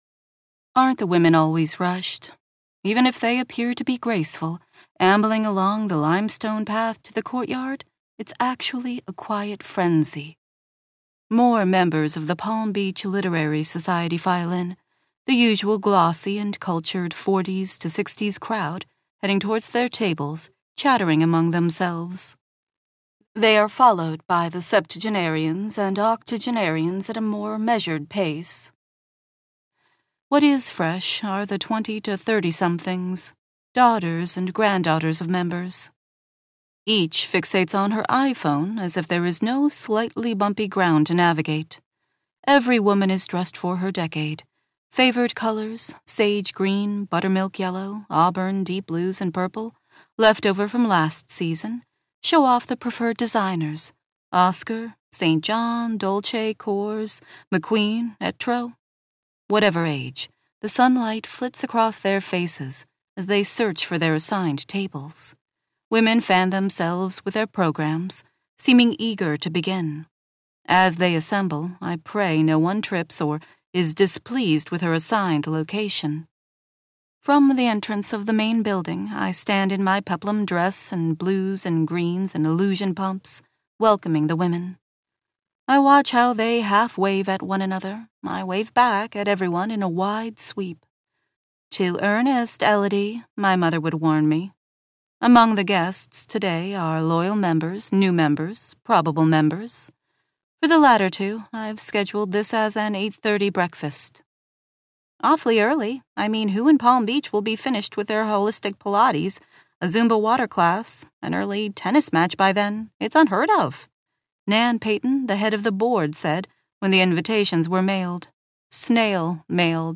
Palm Beach Scandal - Vibrance Press Audiobooks - Vibrance Press Audiobooks